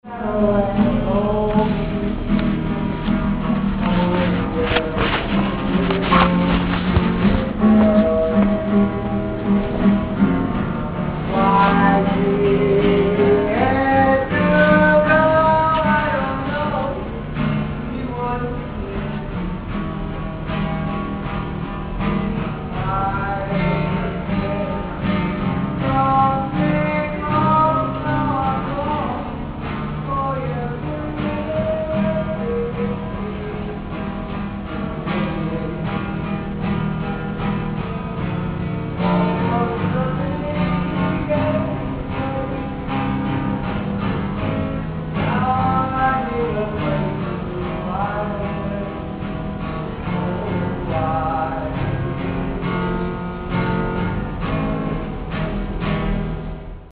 here is a guy singing